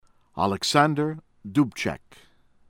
MAH -ree-ahn CHAHL -fah